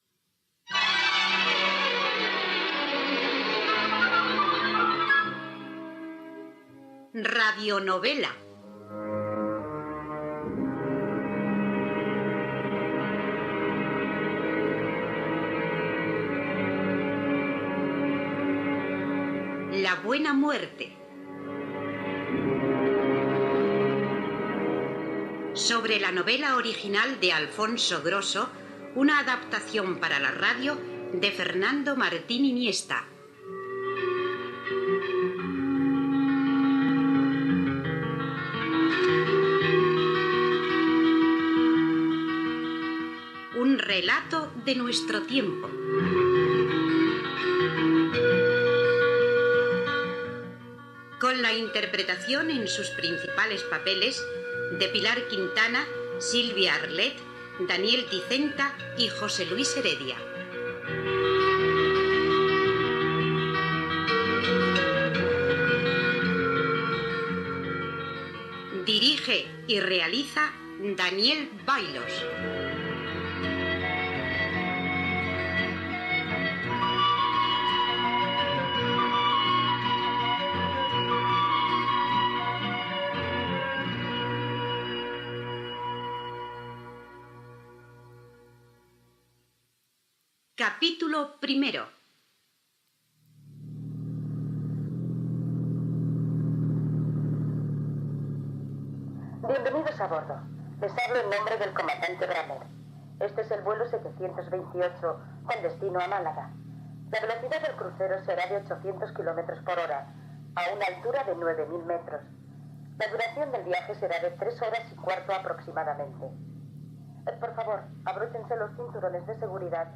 Radio novela
Careta del programa, amb el repartiment, la megafonia d'un vol cap a Màlaga, una trucada telefònica ofereix una feina al protagonista i el narrador explica la vida d'un personatge a París
Ficció